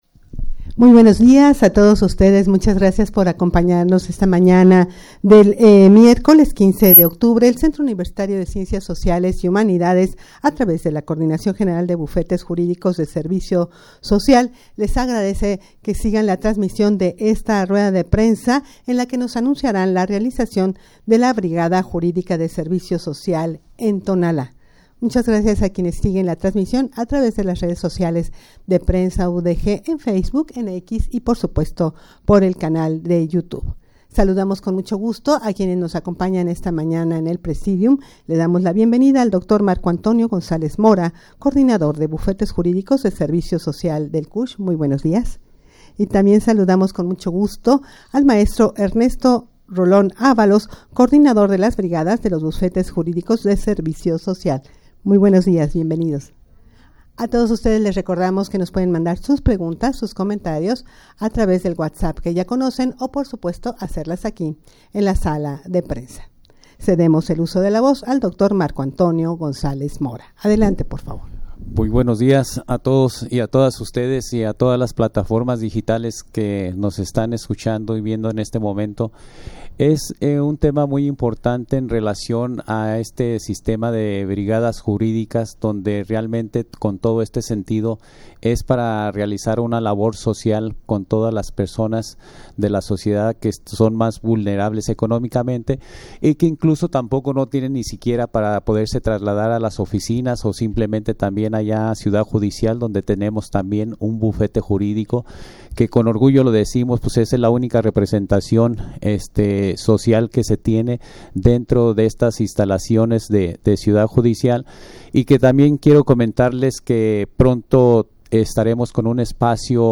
Audio de la Rueda de Prensa
rueda-de-prensa-en-la-que-se-anunciara-la-realizacion-de-la-brigada-juridica-de-servicio-social-en-tonala.mp3